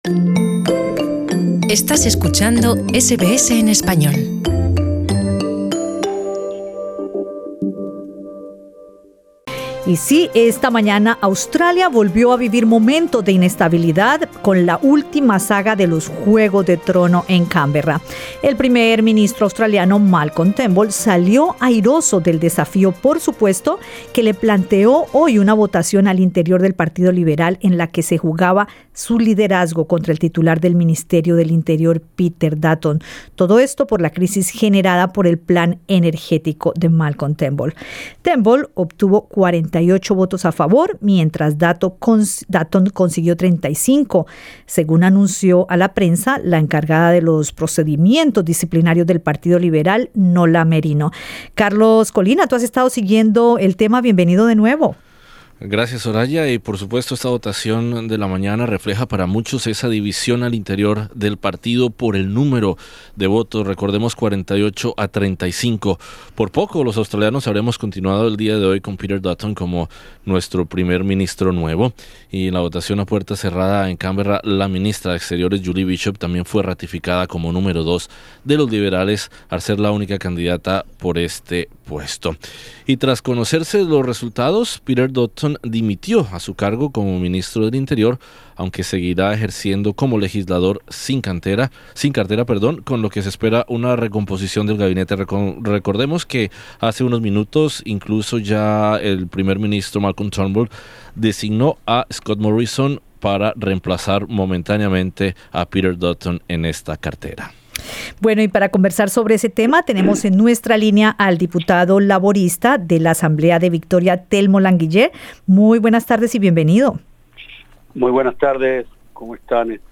Escucha el podcast arriba: conversamos con el diputado laborista del Parlamento de Victoria, Telmo Languiller , quien, basado en la experiencia laborista en la que los ex primeros ministros Kevin Rudd y Julia Guillard se depusieron mutuamente por luchas internas, analiza los escenarios que se le presentan al primer ministro Malcolm Turnbull, después de haber reganado por 48 votos contra 35 el liderazgo de su partido frente al desafío planteado por el ex ministro Peter Dutton .